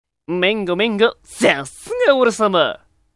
声真似奥義演習場
声マネに関しては似てるモン似てねぇモン多々あります。